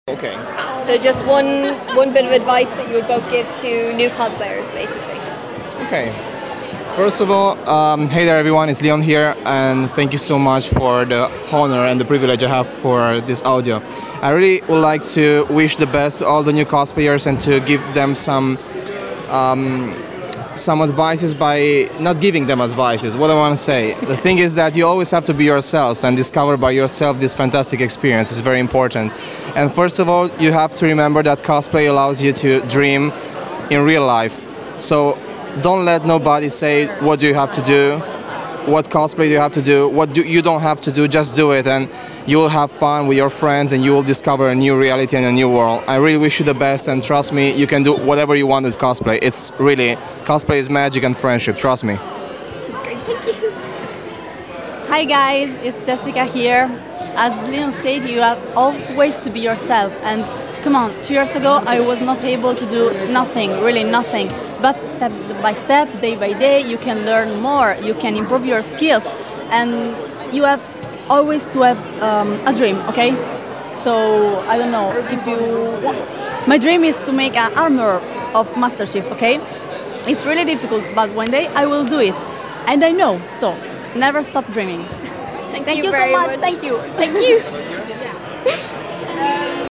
Having just been at AkumaKon down in Galway for the weekend, I personally think this is the best time to go over the dos and don’ts if you’re aiming to get wear out of your cosplay!